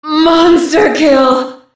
Женский голос убийца чудовищ